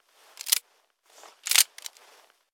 Wpn_pistolmauser_reload.ogg